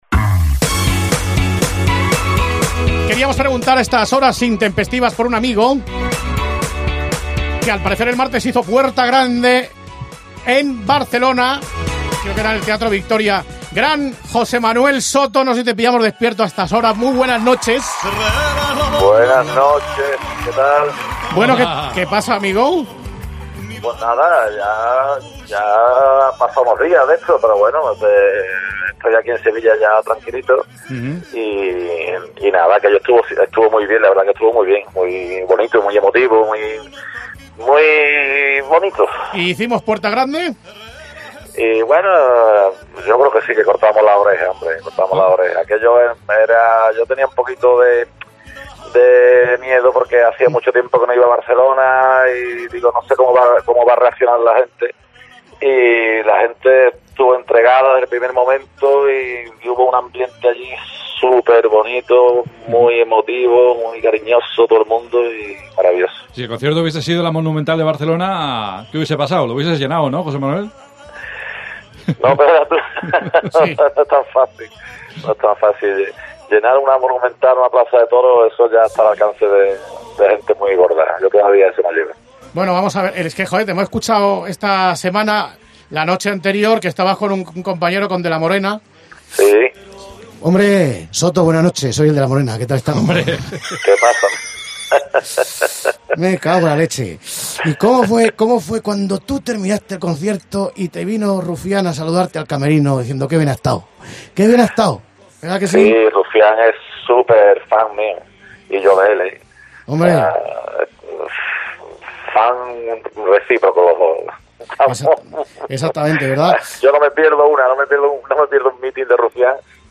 Redacción digital Madrid - Publicado el 23 oct 2018, 09:04 - Actualizado 15 mar 2023, 16:57 1 min lectura Descargar Facebook Twitter Whatsapp Telegram Enviar por email Copiar enlace Llamamos a Jose Manuel Soto después de su actuación en Barcelona, donde cosechó un gran éxito en un momento delicado de la política catalana, sobre todo para alguien abiertamente españolista.